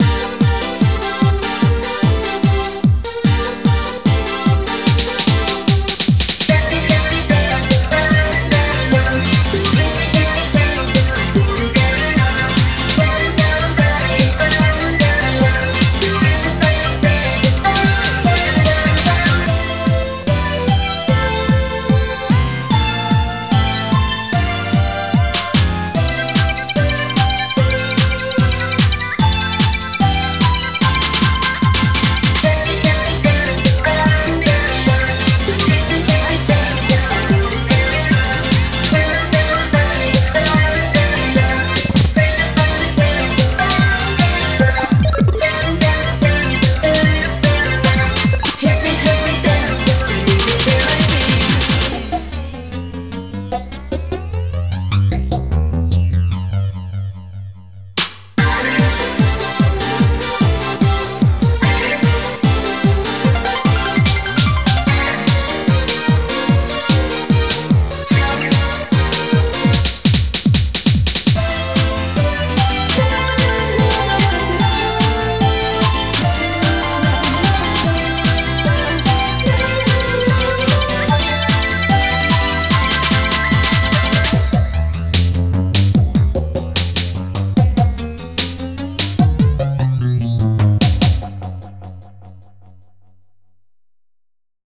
BPM148
Audio QualityPerfect (High Quality)
Better quality audio.